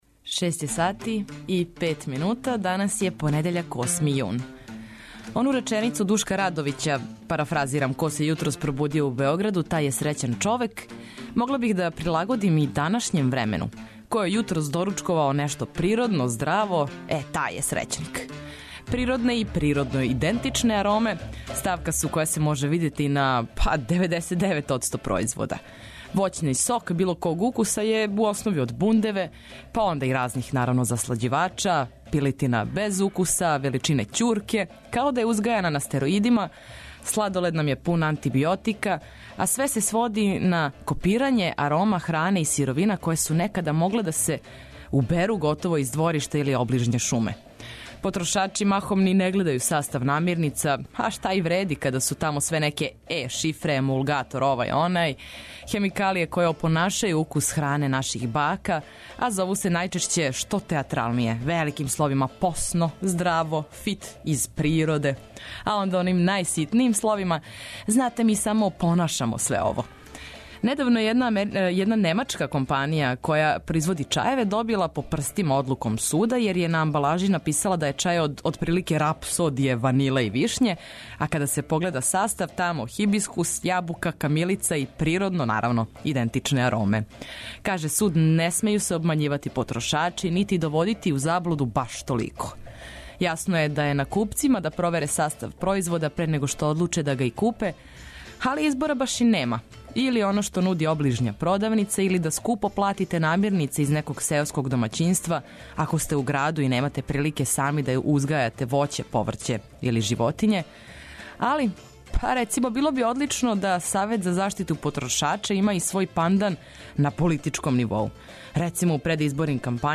Мозак разбуђујемо корисним и интересантним информацијама, а ногу испод покривача - добром музиком!